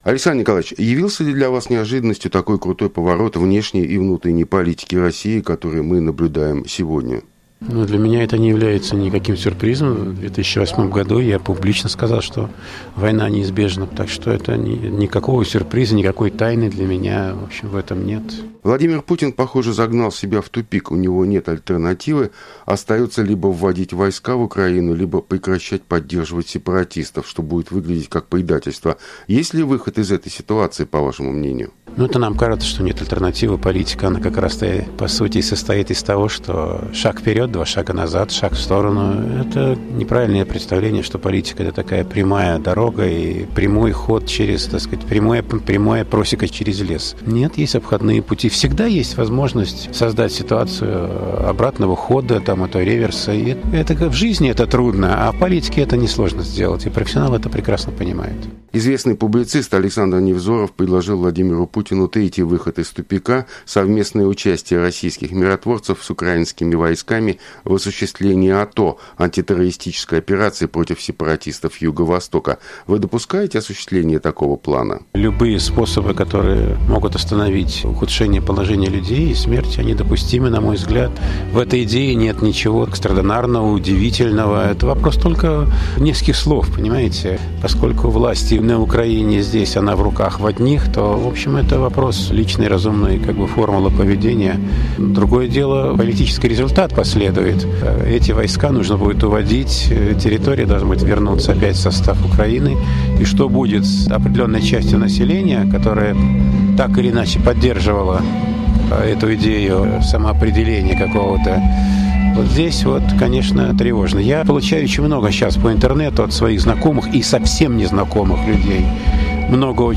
Разговор с Александром Сокуровым